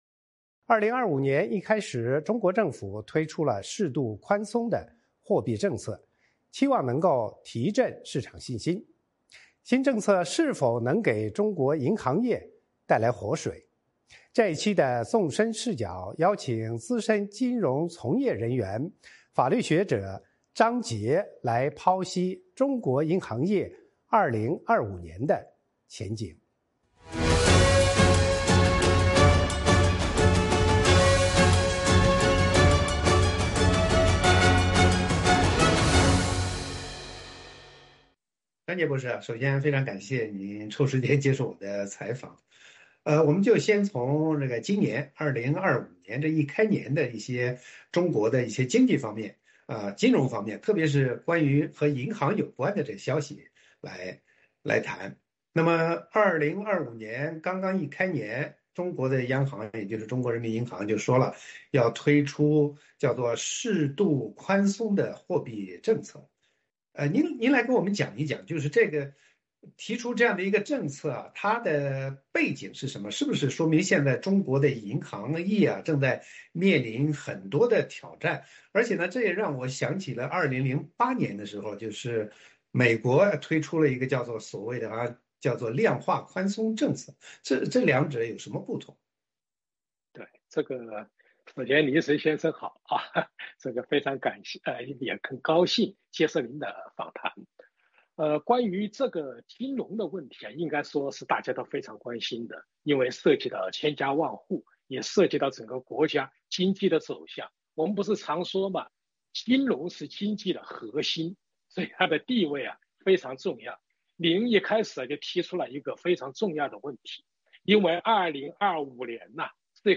《纵深视角》节目进行一系列人物专访，受访者发表的评论不代表美国之音的立场 。